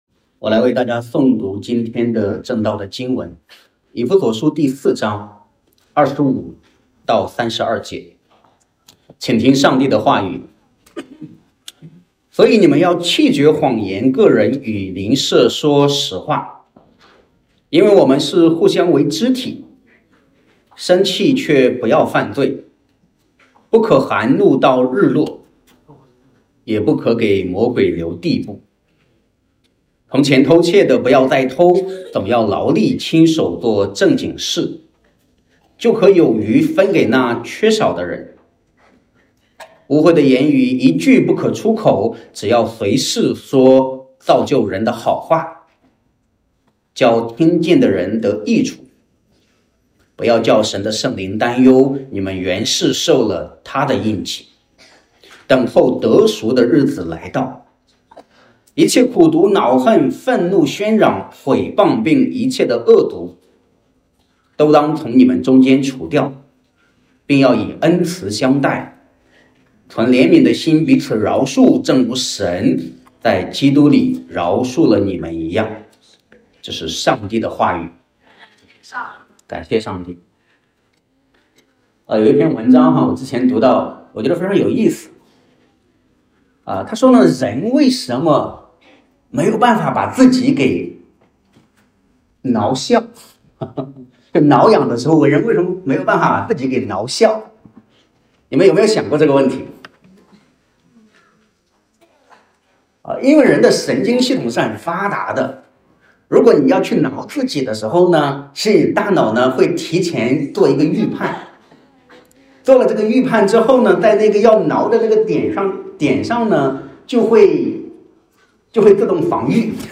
证道：人如何生活（下）.mp3